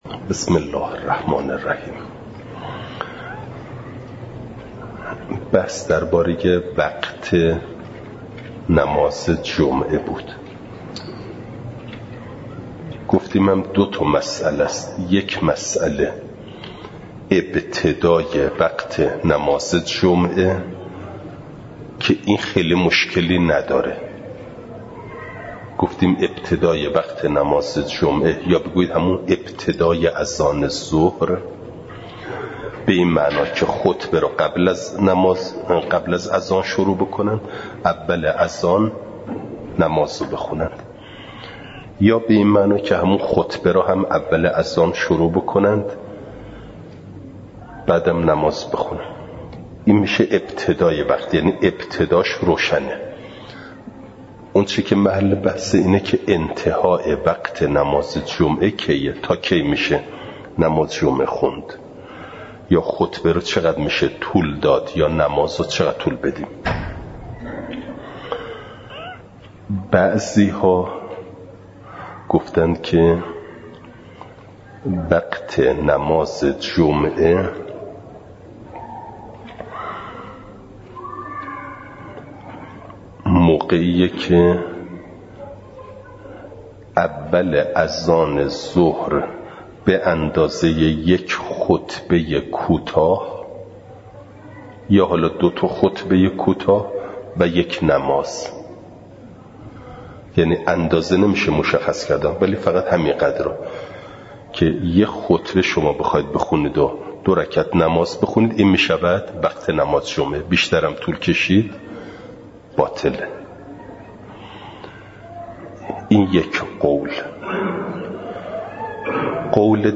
خارج فقه – نماز جمعه (جلسه ۳۰) « دروس استاد